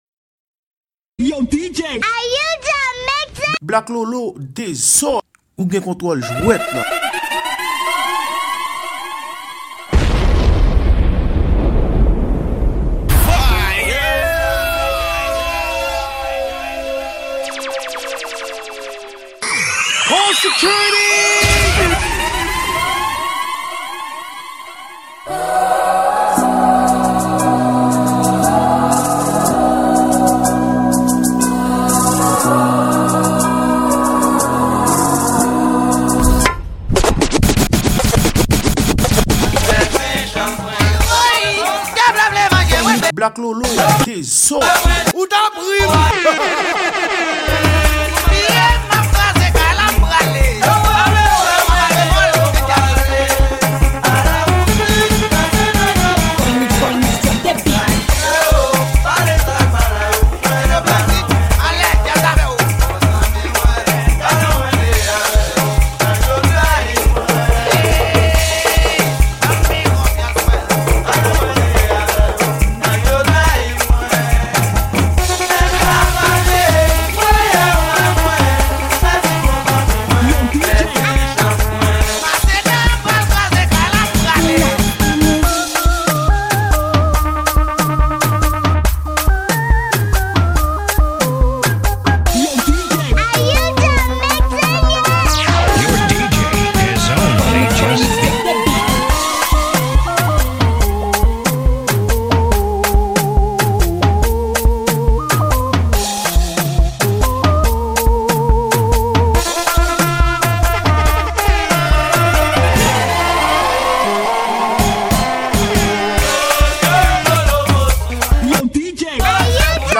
Genre: MIXES.